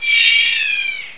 monsters / harpy / dive.wav
dive.wav